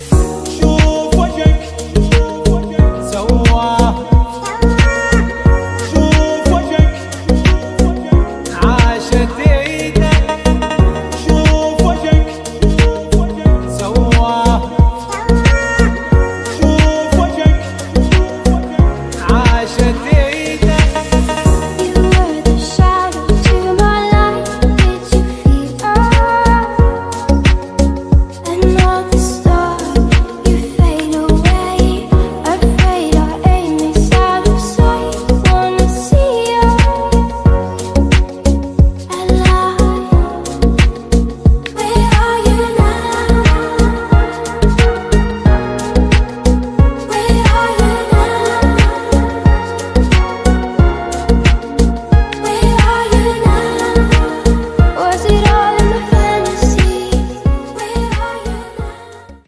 • Качество: 320, Stereo
deep house
красивая мелодия
mash up
арабские